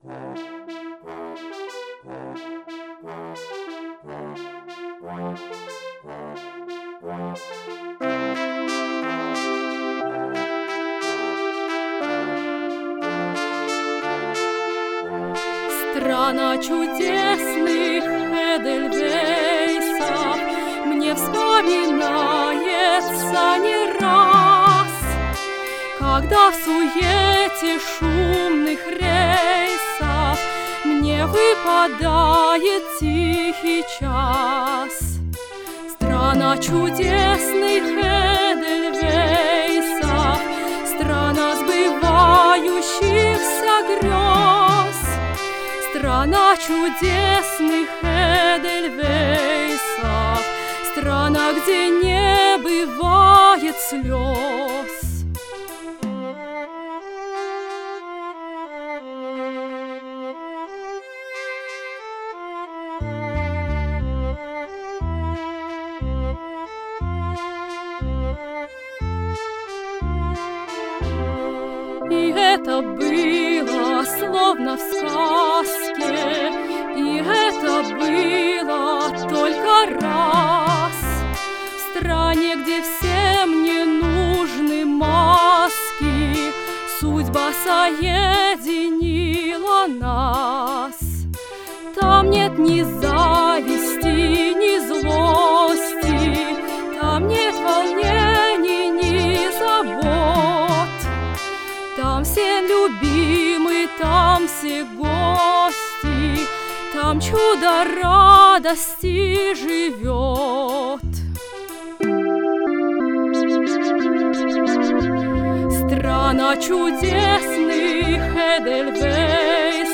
с помощью компьютера и синтезатора